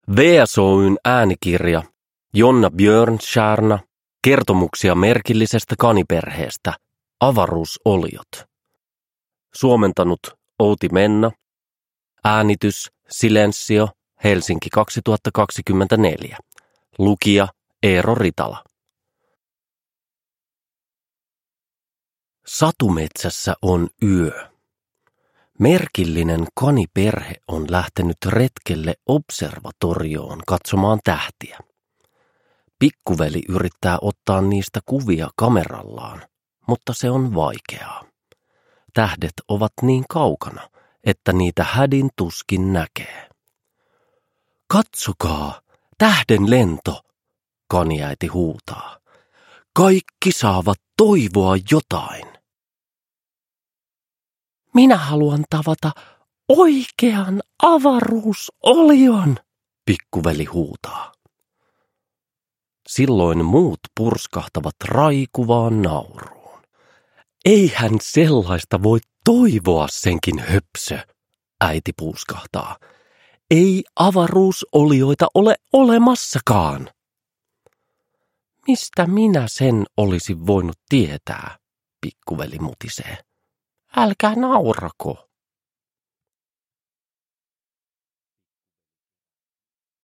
Kertomuksia merkillisestä kaniperheestä: Avaruusoliot – Ljudbok